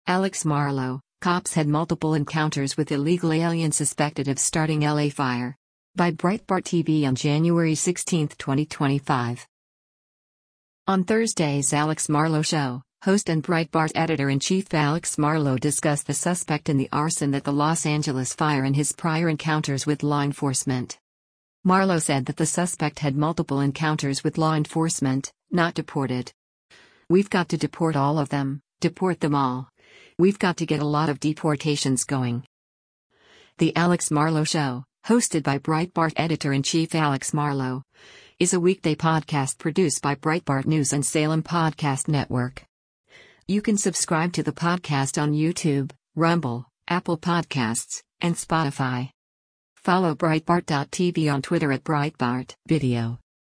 On Thursday’s “Alex Marlow Show,” host and Breitbart Editor-in-Chief Alex Marlow discussed the suspect in the arson that the Los Angeles fire and his prior encounters with law enforcement.
“The Alex Marlow Show,” hosted by Breitbart Editor-in-Chief Alex Marlow, is a weekday podcast produced by Breitbart News and Salem Podcast Network.